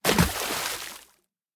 splash1.ogg